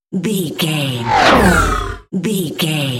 Whoosh electronic shot
Sound Effects
Atonal
bright
futuristic
high tech